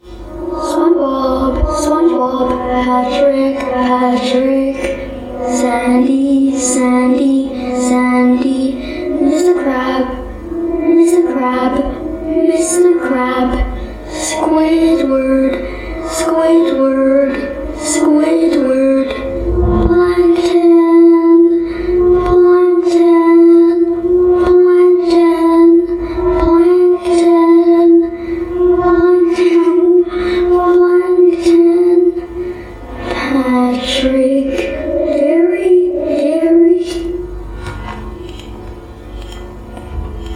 Oof
oof roblox meme sound effect free sound royalty free Memes